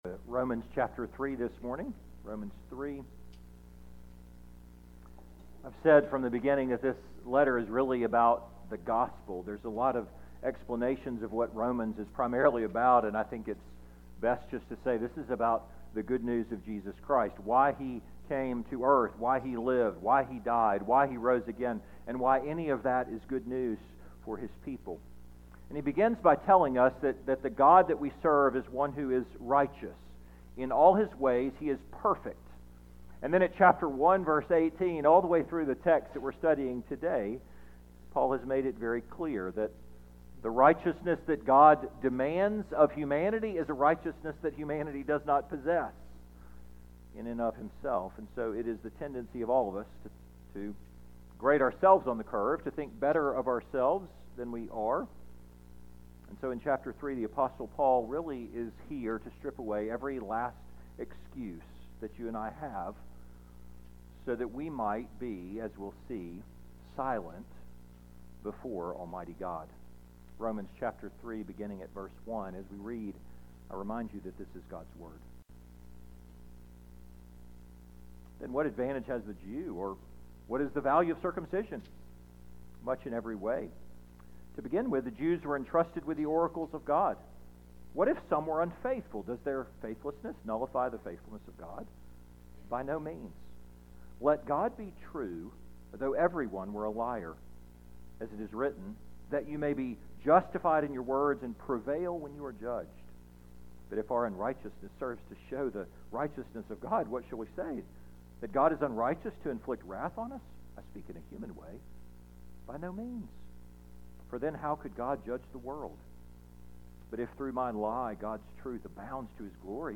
2026 The Sound of Silence Preacher